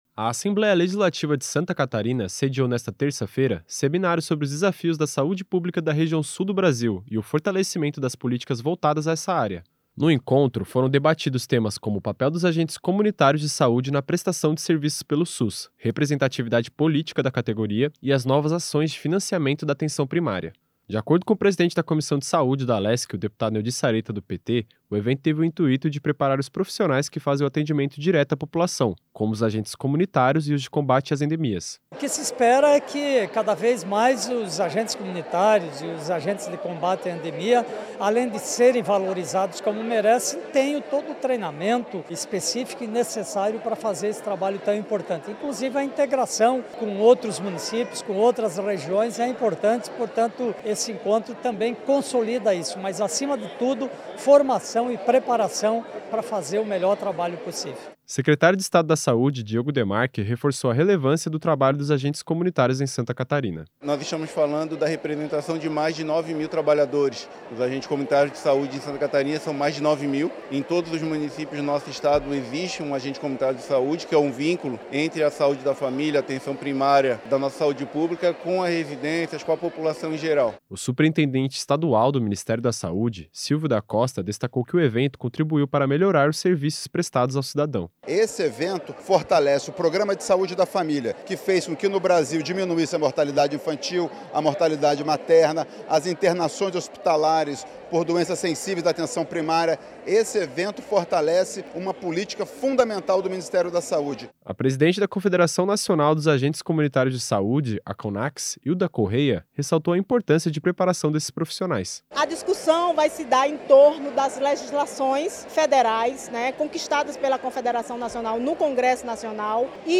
Entrevistas com:
- deputado Neodi Saretta (PT), presidente da Comissão de Saúde da Assembleia Legislativa;
- Diogo Demarchi, secretário de Estado da Saúde;